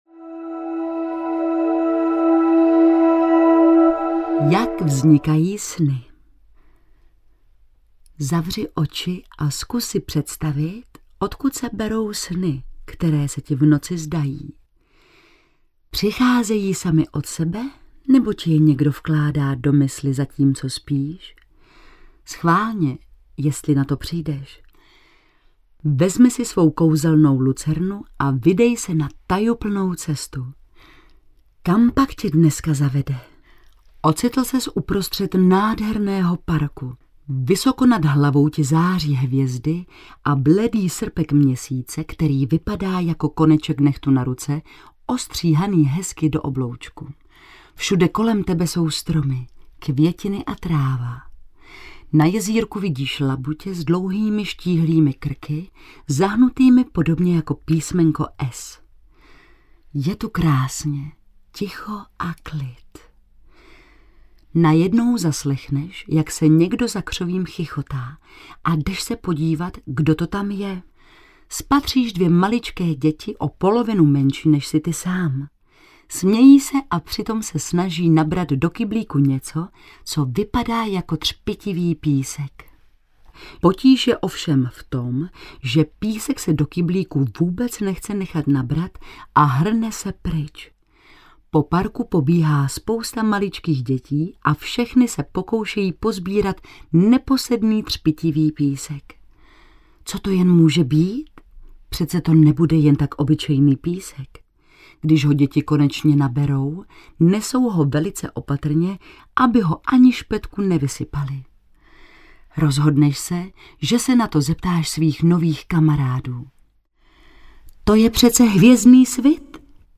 Kouzelná lucerna audiokniha
Ukázka z knihy